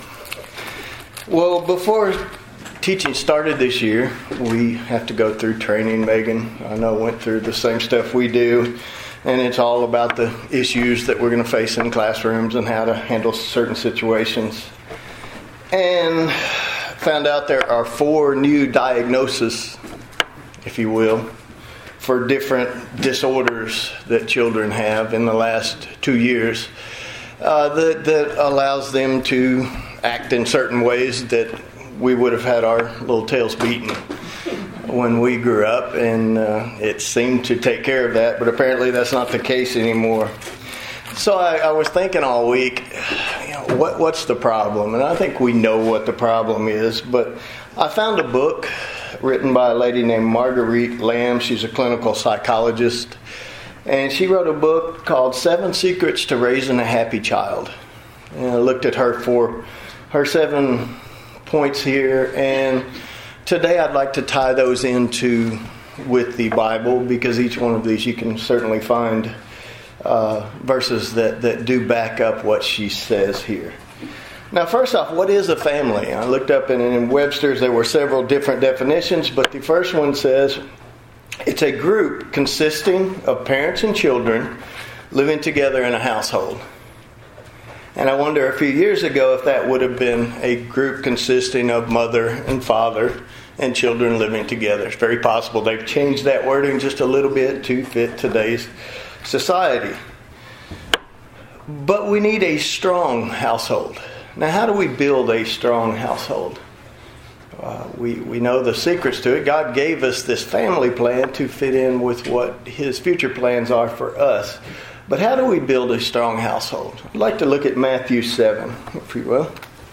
Sermons
Given in Murfreesboro, TN